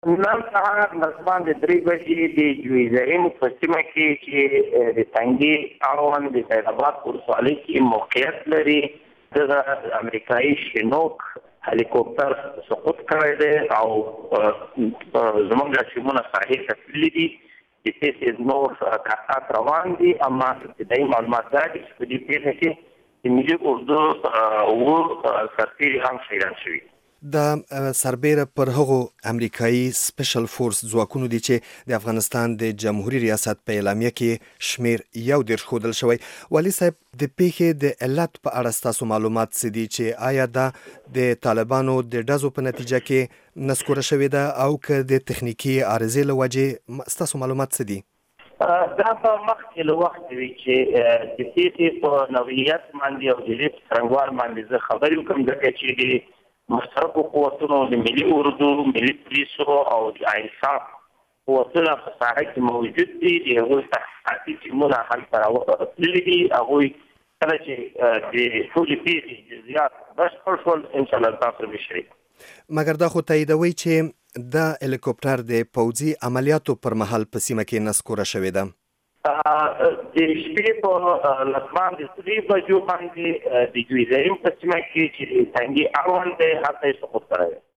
د وردګ له والي سره مرکه